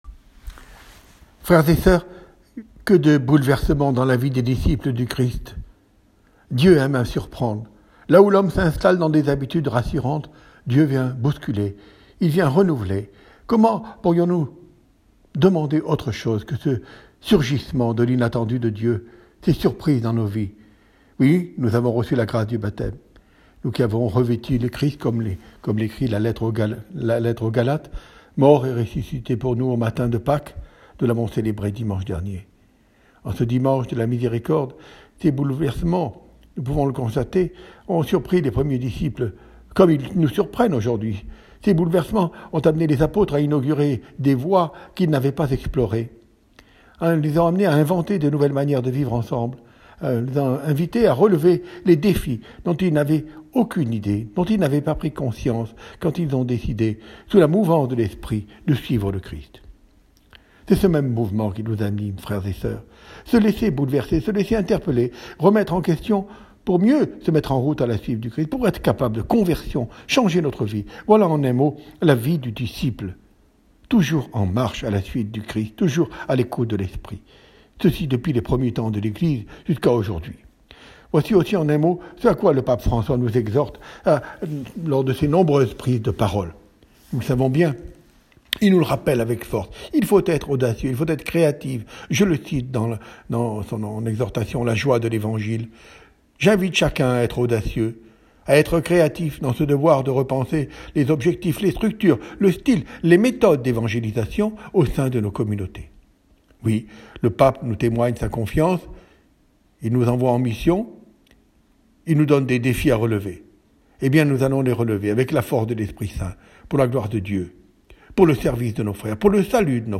Homélie de Monseigneur Colomb à écouter :